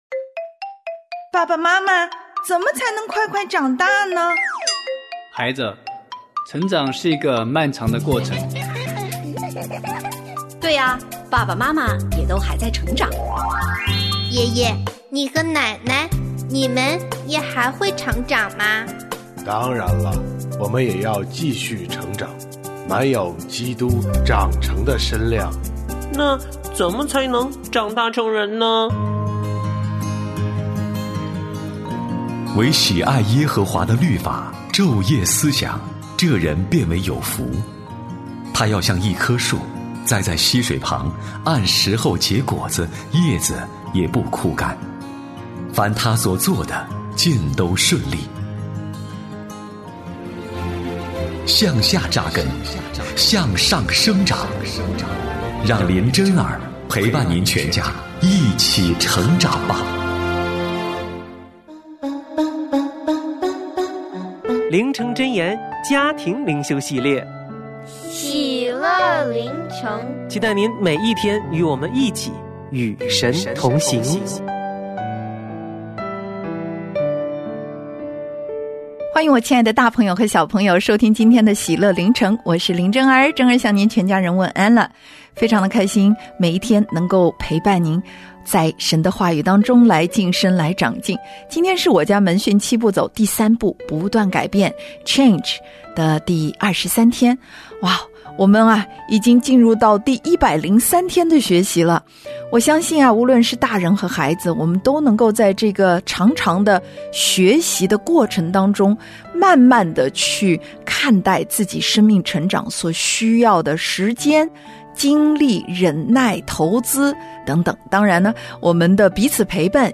我家剧场：圣经广播剧（99）米非波设向大卫辩白；巴西莱给大卫送行